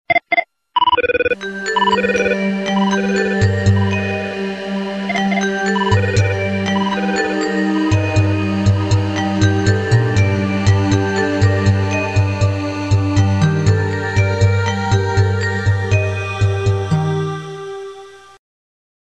eletronic-ring-mix_24699.mp3